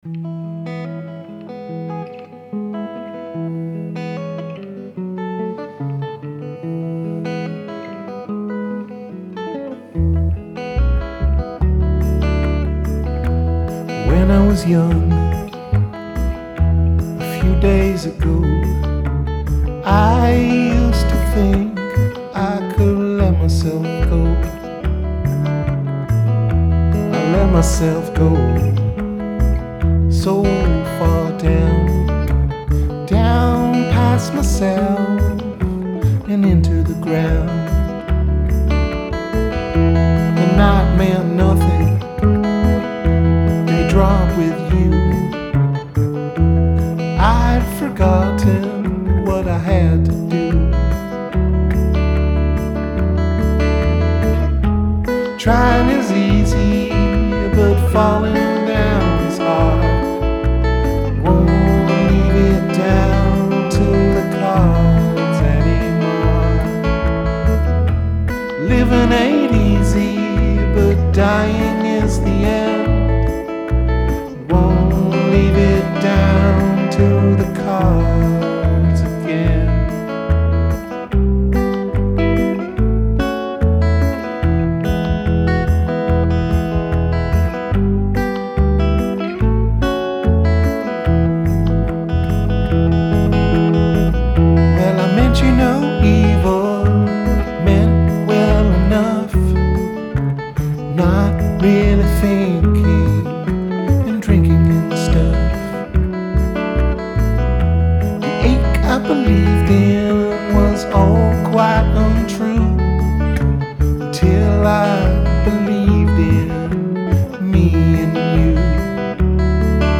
Rehearsals 27.3.2012